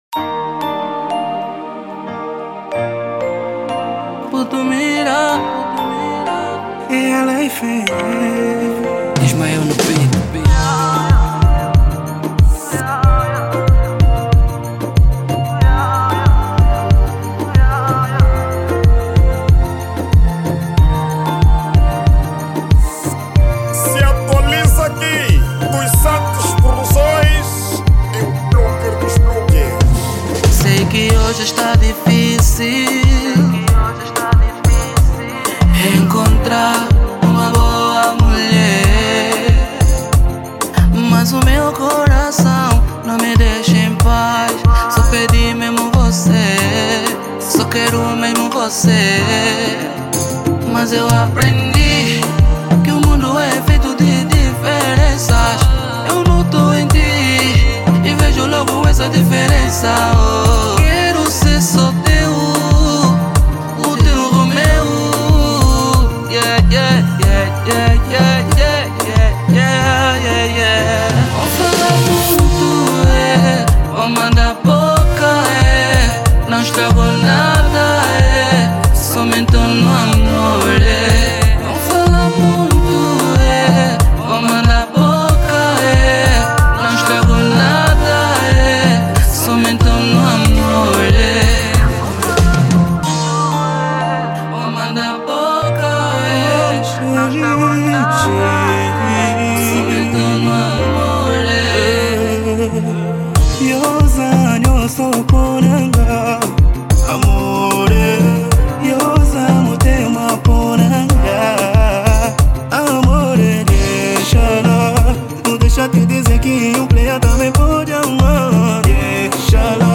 ESTILO DA MÚSICA:  Zouk
MUSIC STYLE: ZOUK